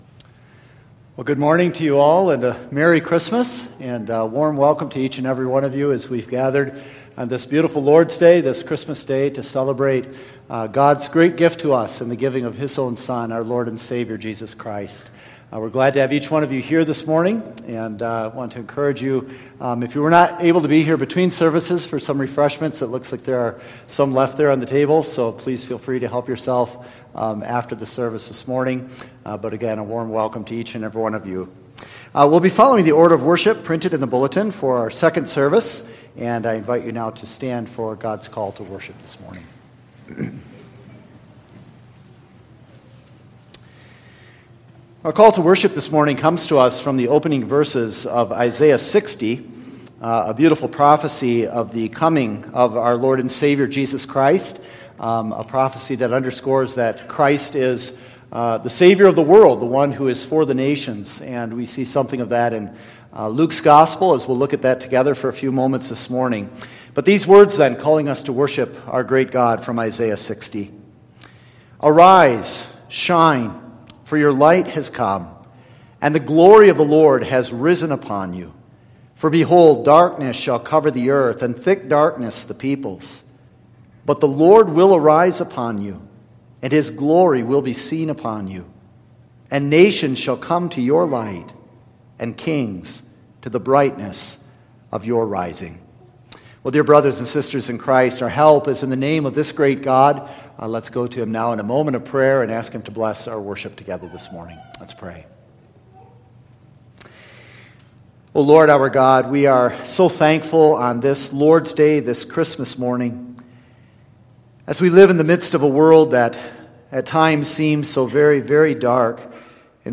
All Sermons Heaven’s Song December 25